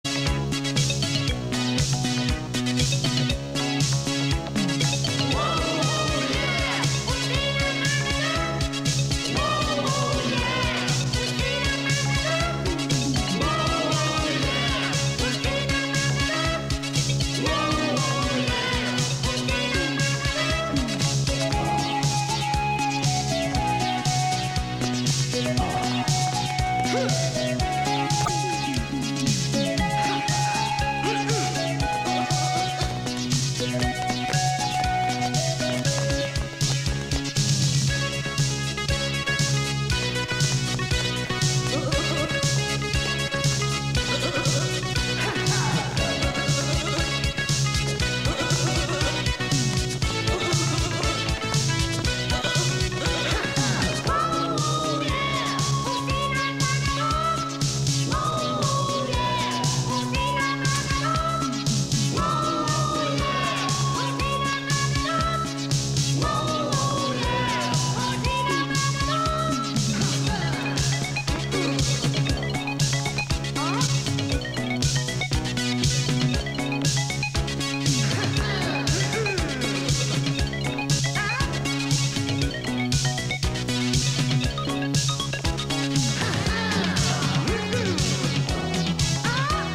В неизвестном музыкальном треке звучит фраза на непонятном языке.